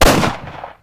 akimbofire2.wav